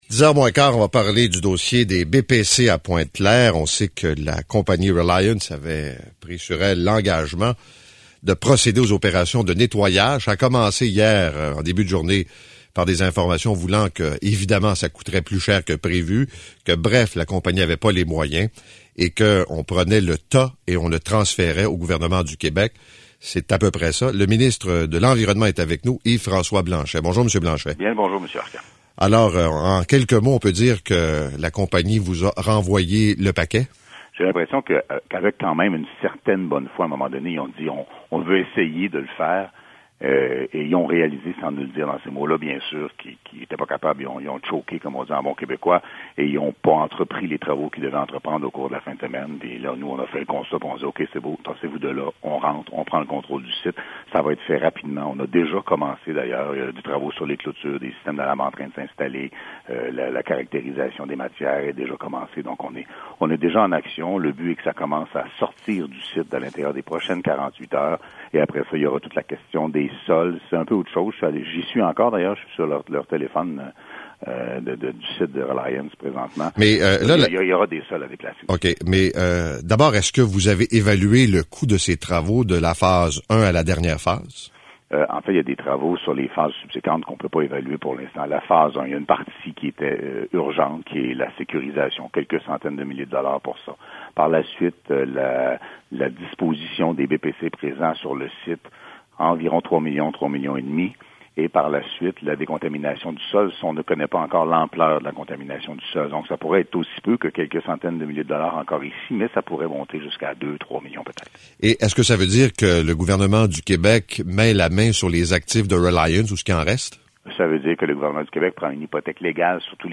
La courte entrevue de Paul Arcand avec le ministre de la propreté du complet-veston, 17 septembre 2013 :